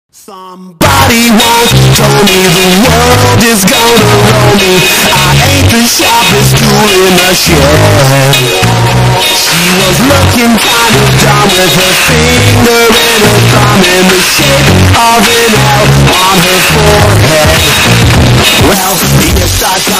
ОЧЕНЬ ГРОМКОЕ